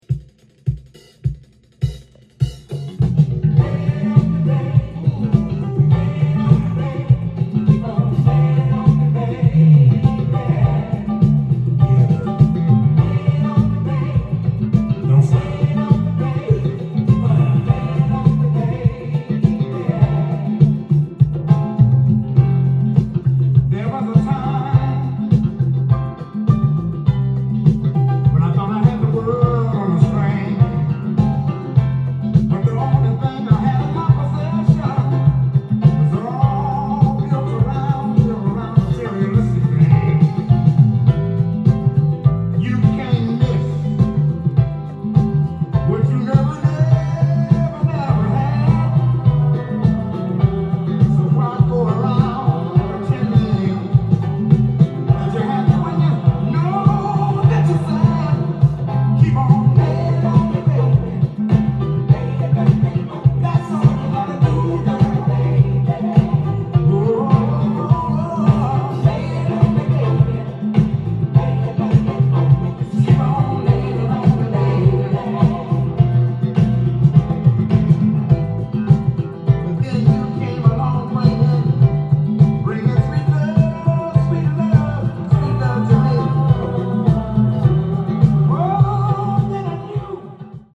店頭で録音した音源の為、多少の外部音や音質の悪さはございますが、サンプルとしてご視聴ください。
名ソウル・シンガー